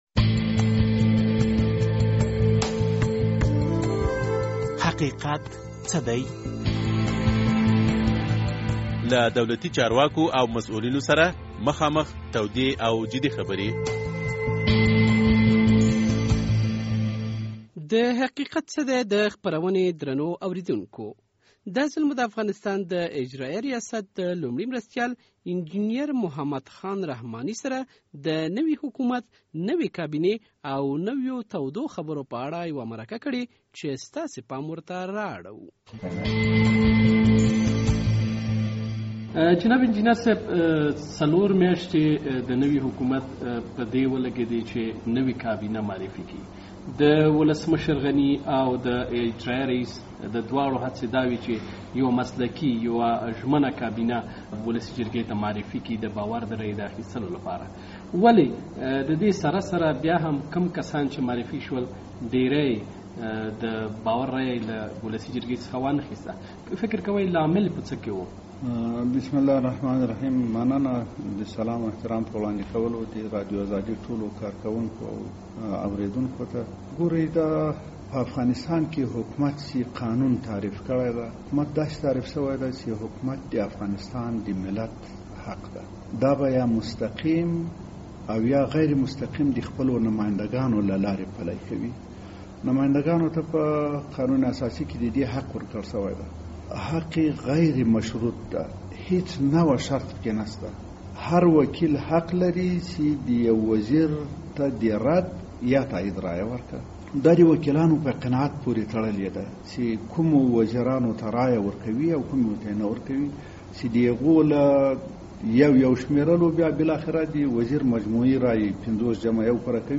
د حقیقت څه دی په دې خپرونه کې د افغان دولت د اجرایه ریاست د لومړي مرستیال انجنیرمحمد خان رحماني سره مرکه شوې ده.